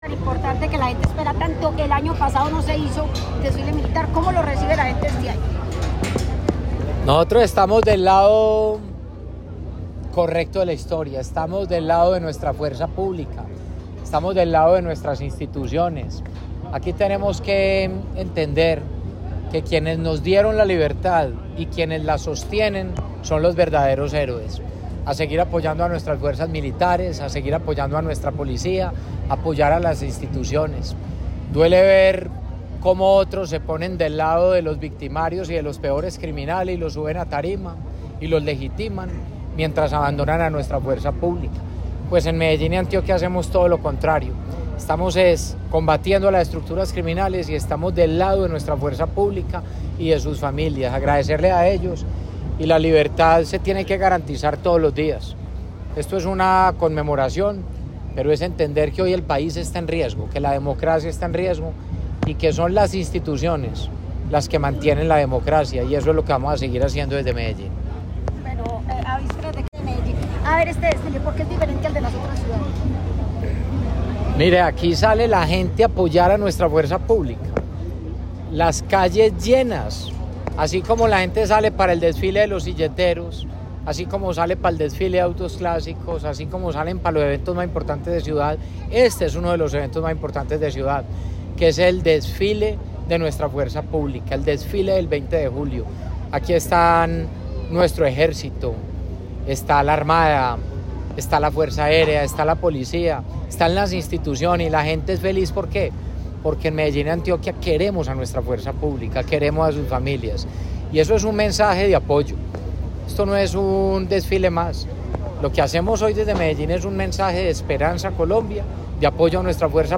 En medio de un emotivo y colorido desfile militar, que recorrió parte de la ciudad, Medellín celebró los 215 años de la Independencia de Colombia con un llamado a luchar por la libertad y cuidar la democracia. Durante el acto, el alcalde Federico Gutiérrez Zuluaga, rindió homenaje a los integrantes de la Fuerza Pública que cada día, con heroísmo, defienden la patria.
audio-desfile20dejulio-alcaldefederico-gutierrez.mp3